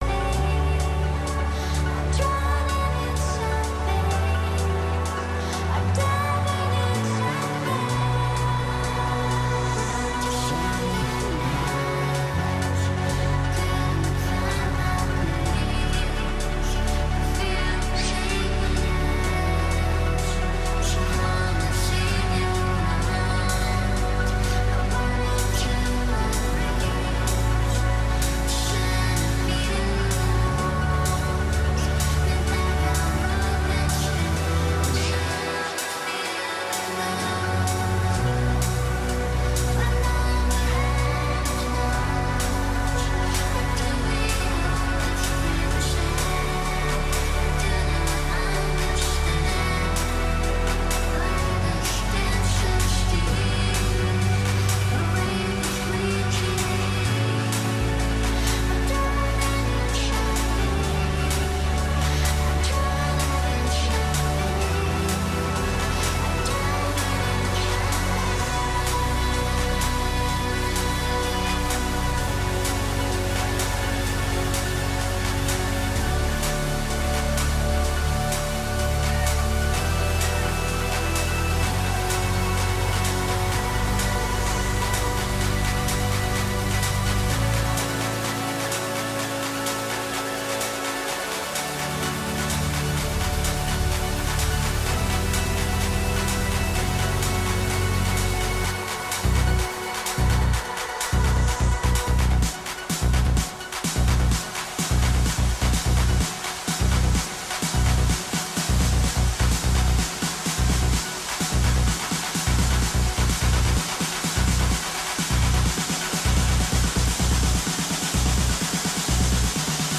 DJ mix or Live Set